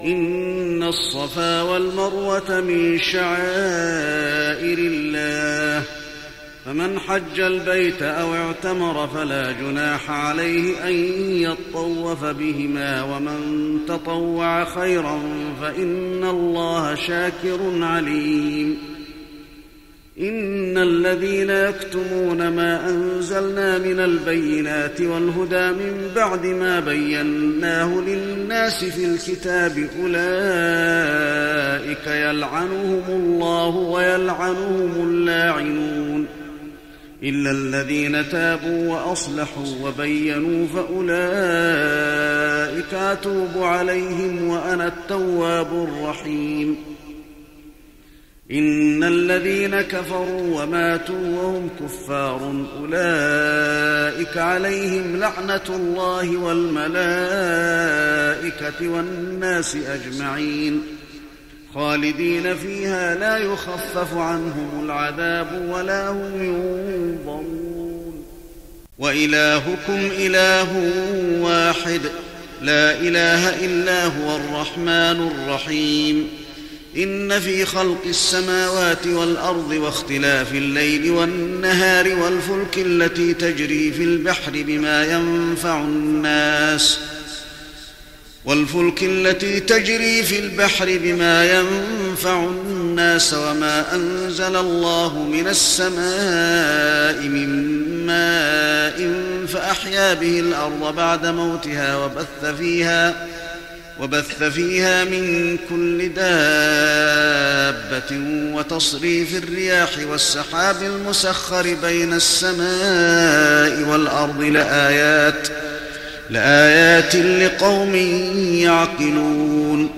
تهجد رمضان 1415هـ من سورة البقرة (158-203) Tahajjud night Ramadan 1415H from Surah Al-Baqara > تراويح الحرم النبوي عام 1415 🕌 > التراويح - تلاوات الحرمين